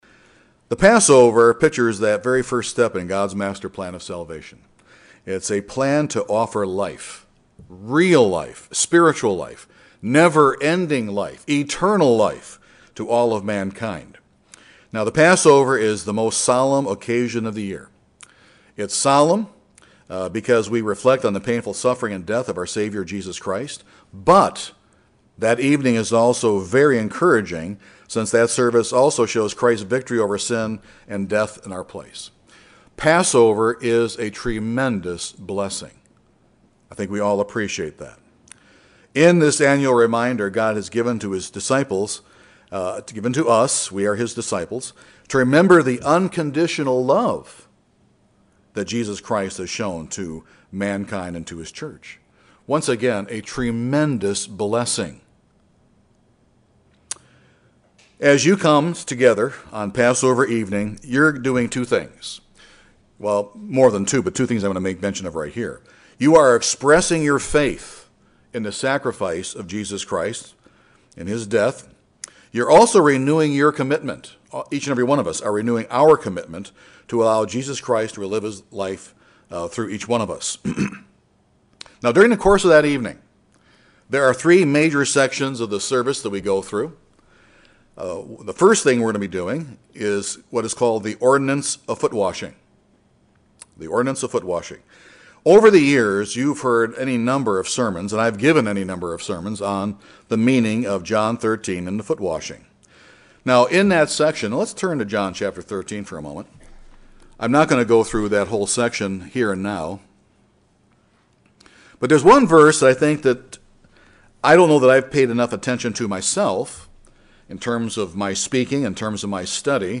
In referring to the foot washing of John 13 Jesus Christ said that we would be blessed if we washed feet. This sermon covers, in some detail, the blessings that come from humility.